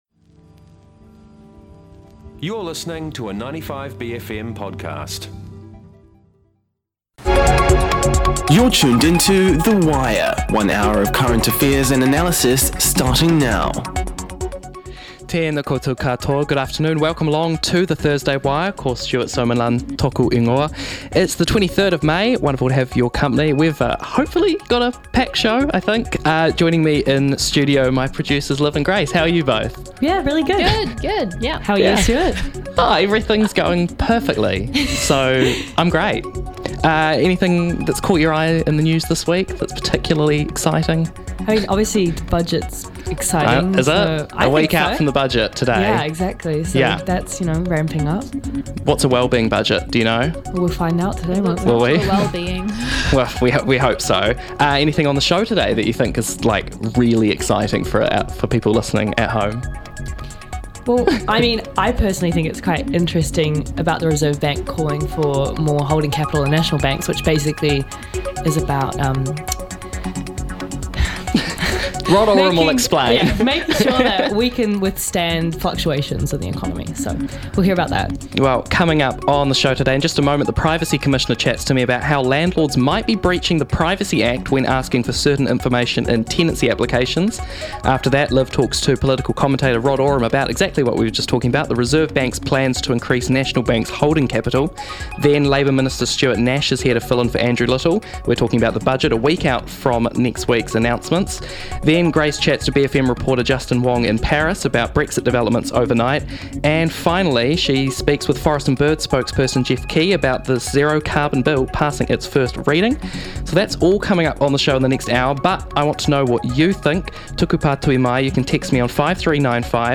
Coming up on the show today: The Privacy Commissioner chats to me about how landlords might be breaching the privacy act when asking for certain information in tenancy applications.